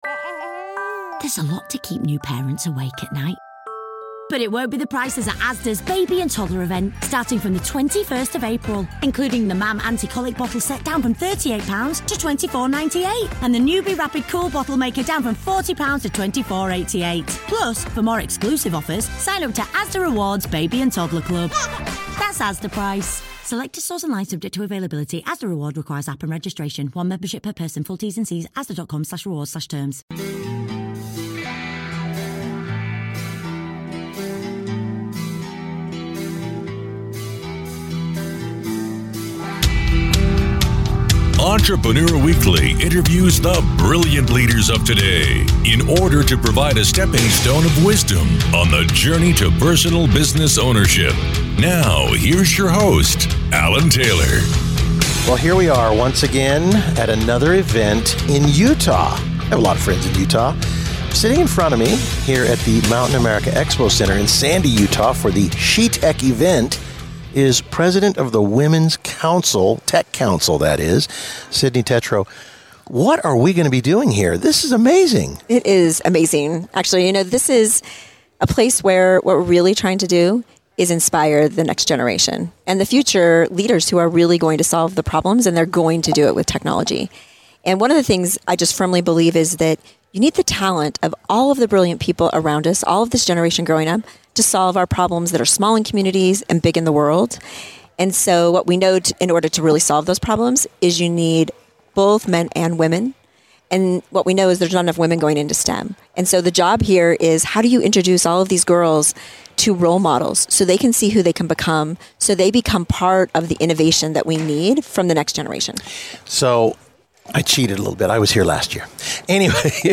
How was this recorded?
SheTech Event 2025 Mountain America Expo Center " broadcasting from the Mountain America Expo Center in Sandy, Utah, during the SheTech event, which gathers around 3,000 high school girls and 1,000 mentors to inspire young women in tech.